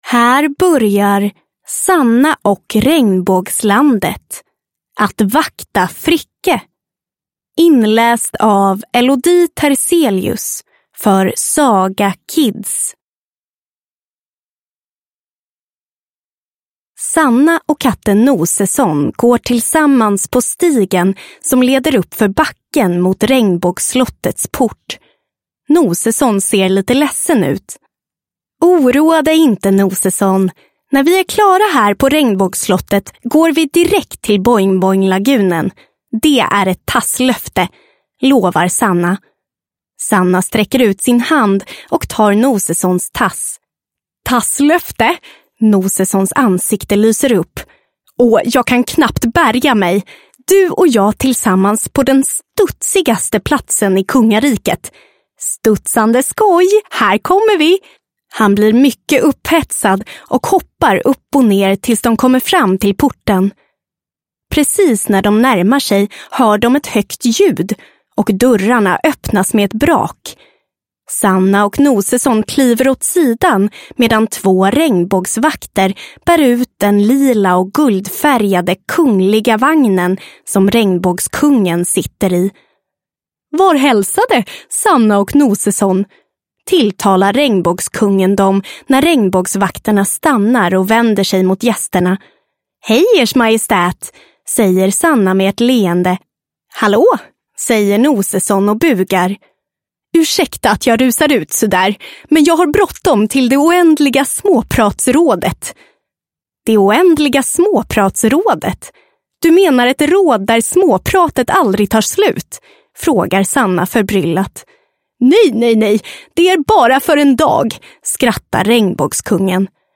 Sanna och Regnbågslandet – Att vakta Fricke – Ljudbok